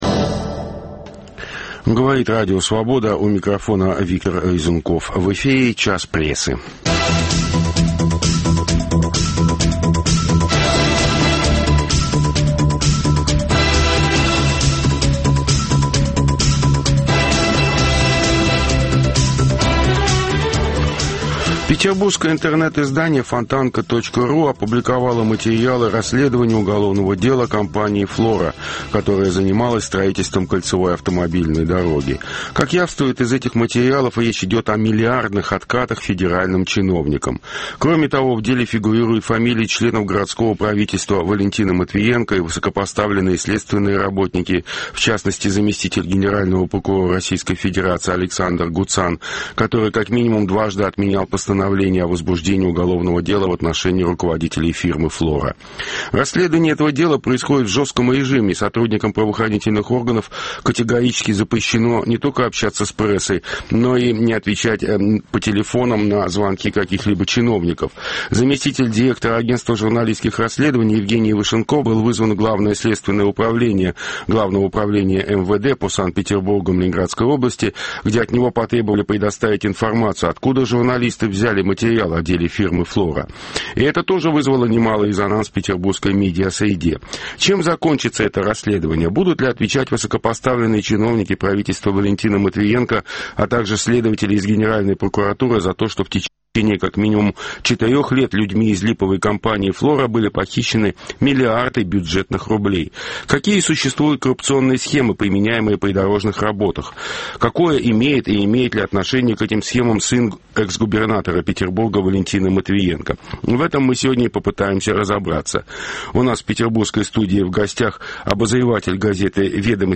по тел. из Лондона